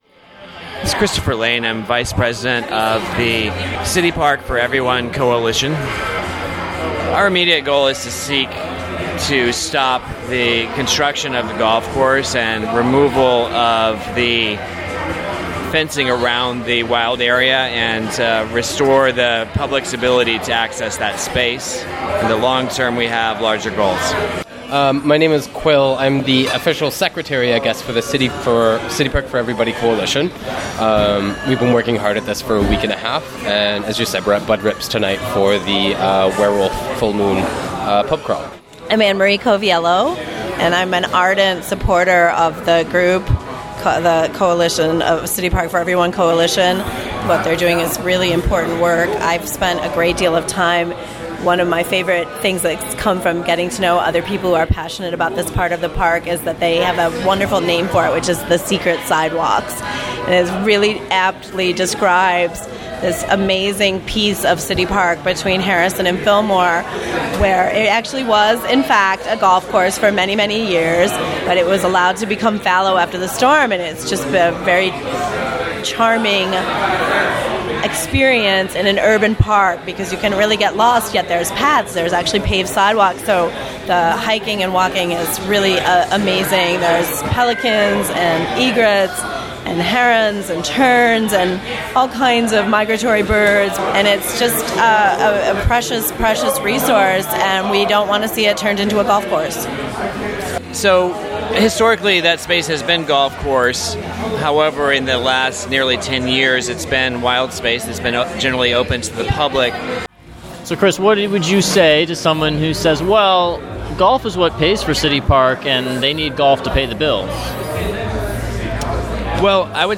Members of the City Park for Everyone coalition speak out about the golf course construction that just began in the park.